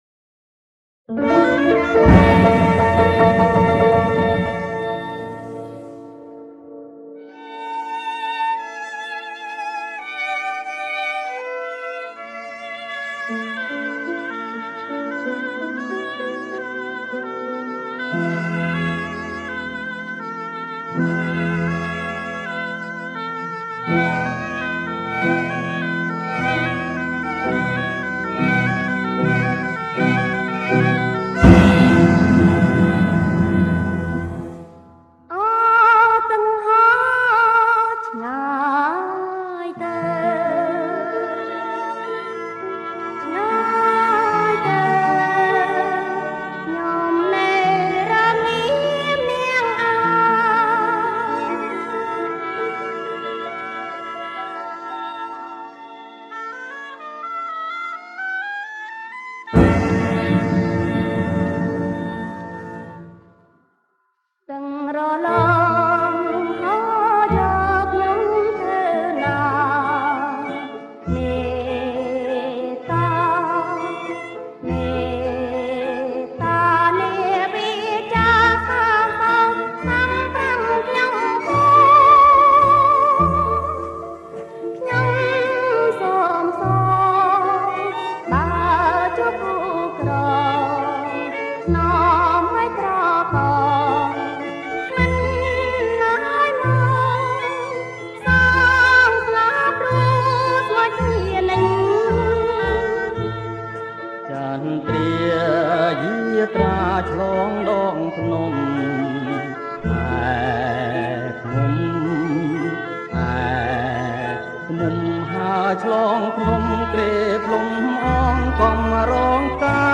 • ប្រគំជាចង្វាក់ Blue